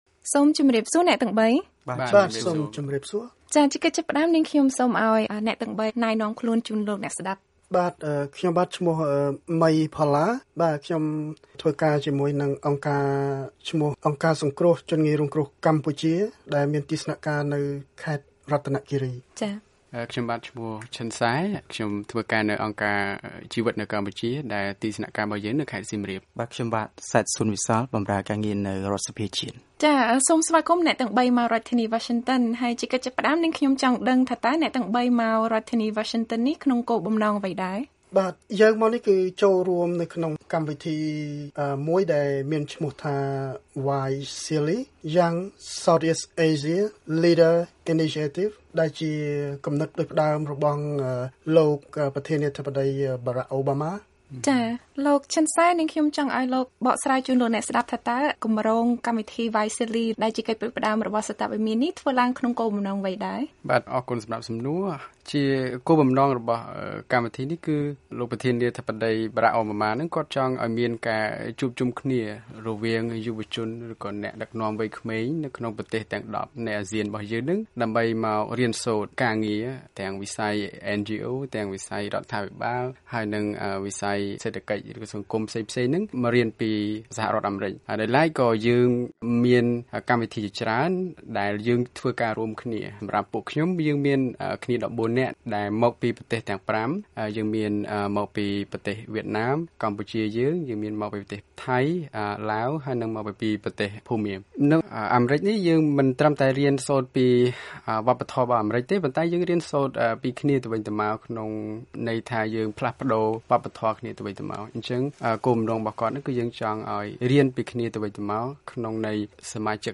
បទសម្ភាសន៍ជាមួួយសមាជិក YSEALI៖ សារៈសំខាន់នៃទំនាក់ទំនងល្អរវាងរដ្ឋាភិបាល និងសង្គមស៊ីវិល